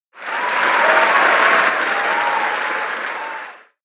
Crowd - Cheering